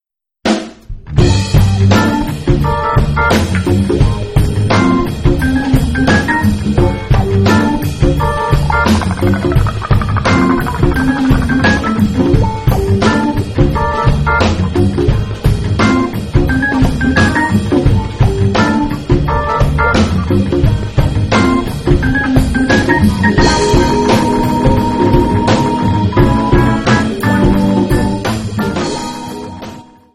Funk
Jamband
Jazz